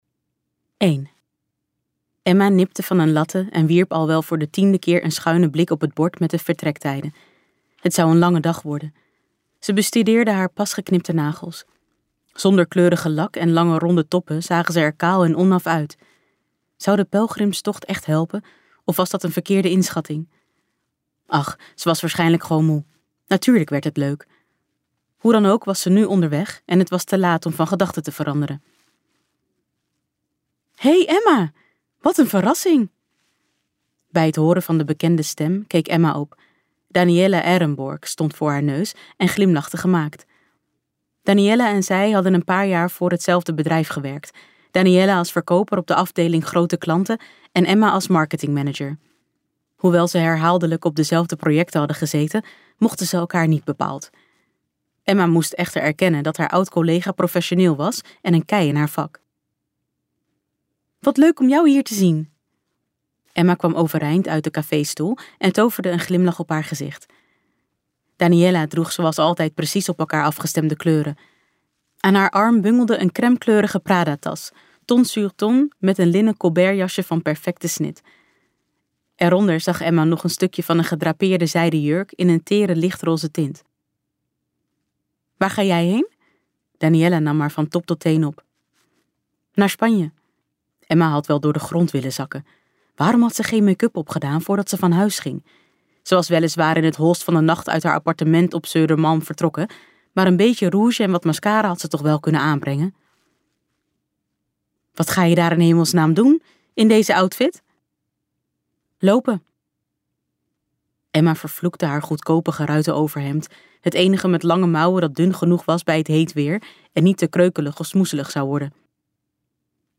Onder de sterrenhemel luisterboek | Ambo|Anthos Uitgevers